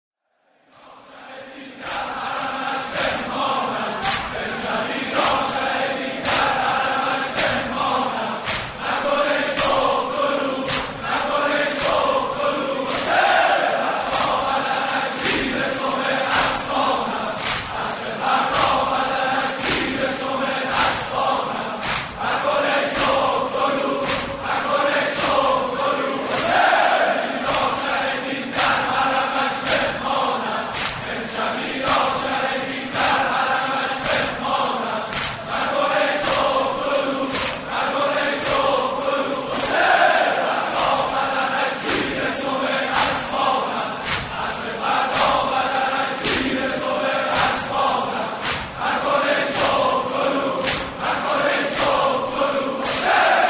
دودمه "مکن ای صبح طلوع"؛ یادگاری از پدر آیت الله بهجت(ره) - تسنیم